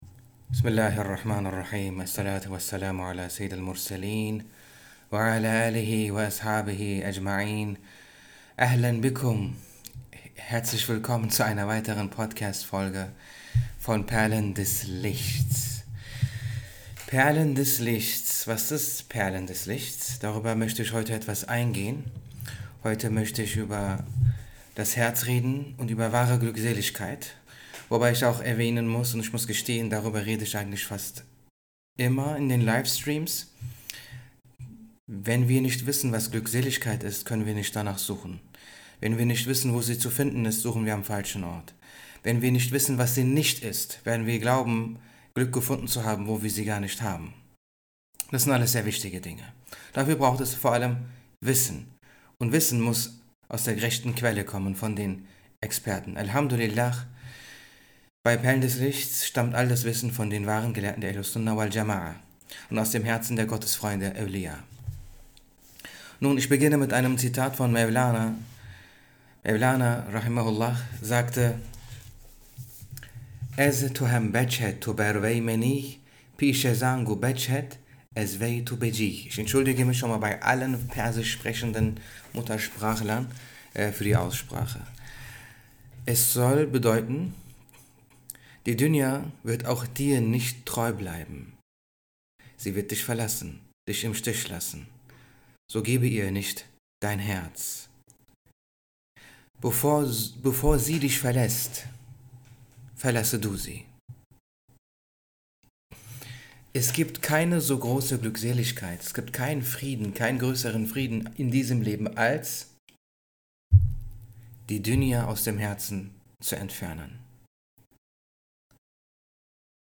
Stil & Sprache: Sanft, poetisch und tief.